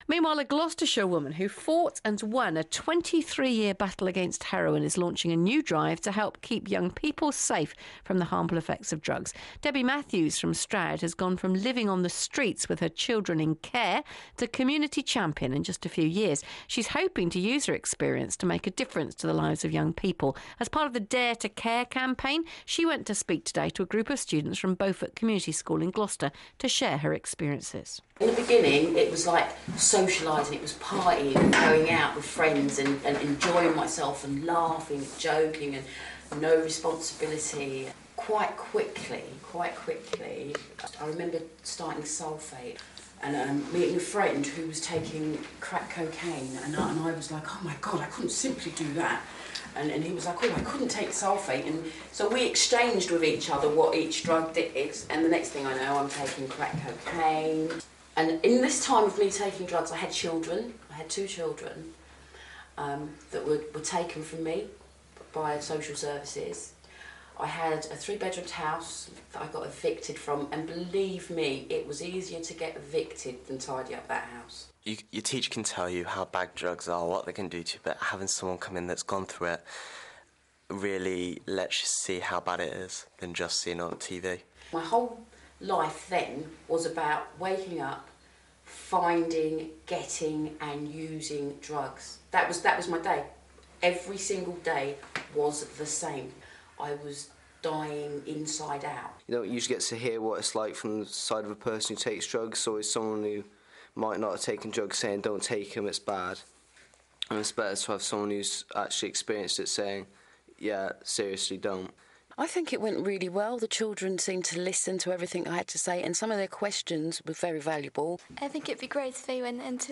This interview was featured on BBC Gloucestershire in 2008